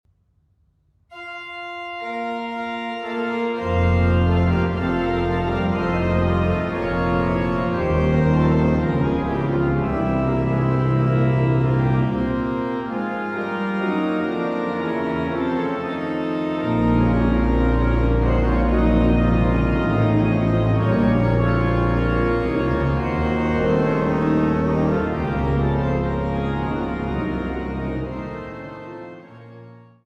Canon alla Quinta, Adagio